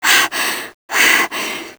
f_ex_breath.wav